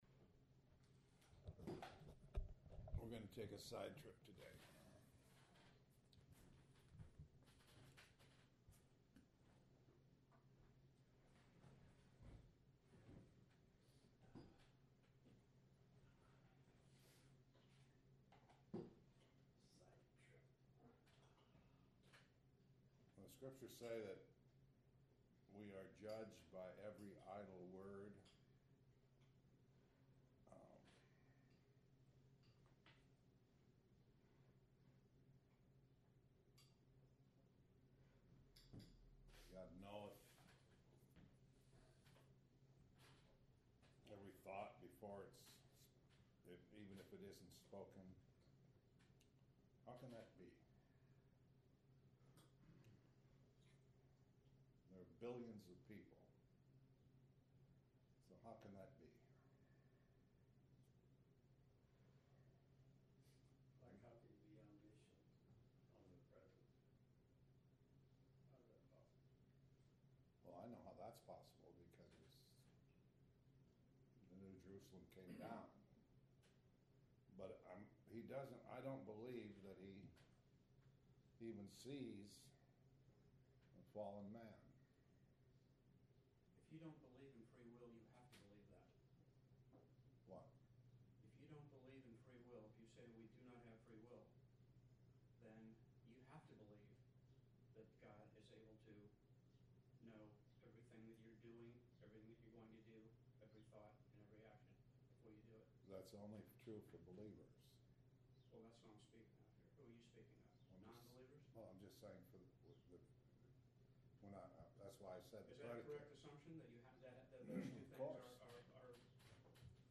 This entry was posted in Morning Bible Studies .